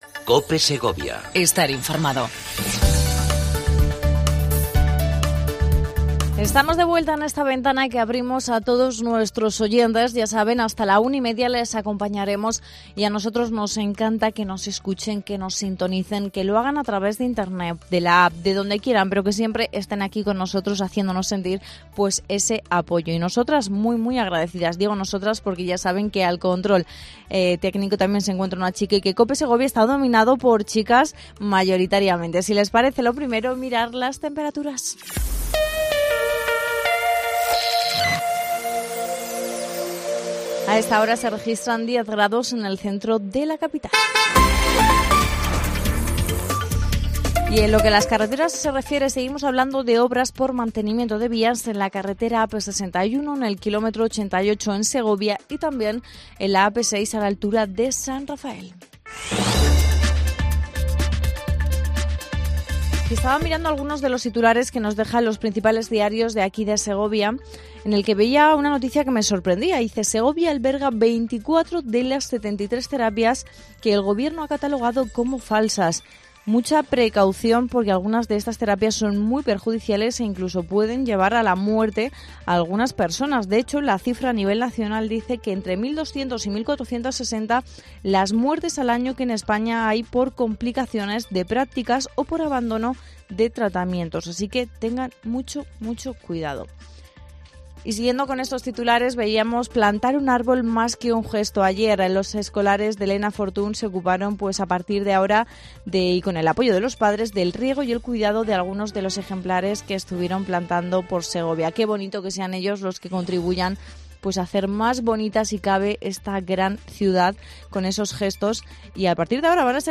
AUDIO: Entrevista a Marian Rueda, Concejala de Deportes de la capital Segoviana.